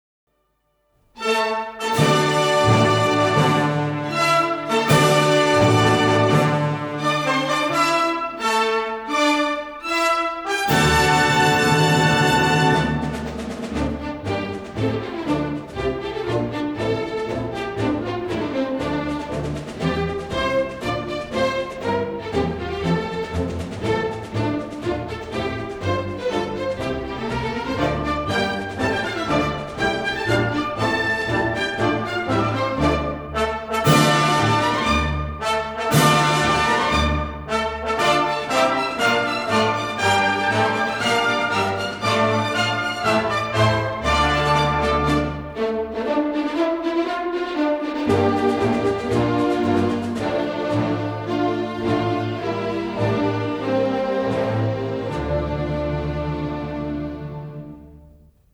真空管MANLEY重新混音版